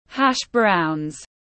Khoai tây sợi chiên giòn tiếng anh gọi là hash browns, phiên âm tiếng anh đọc là /ˌhæʃ ˈbraʊnz/
Hash browns /ˌhæʃ ˈbraʊnz/